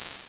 Modular Open Hat 02.wav